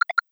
GenericNotification2.wav